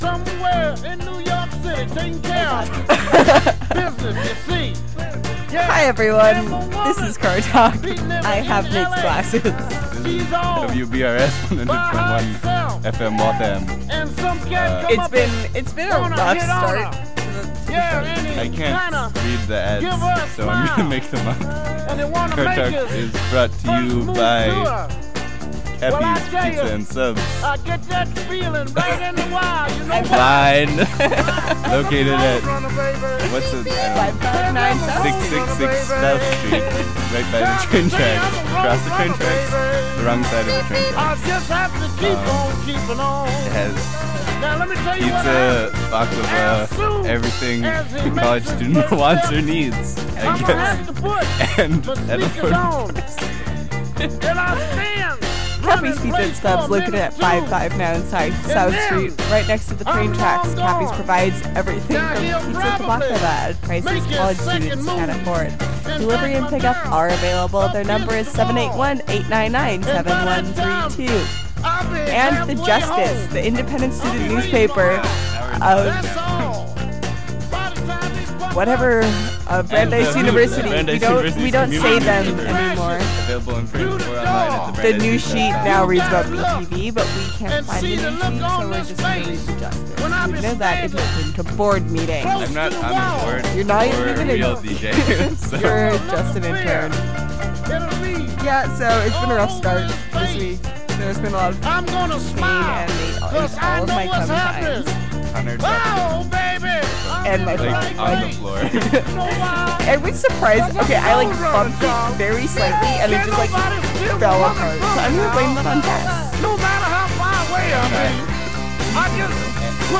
Join us for a struggle filled car talk.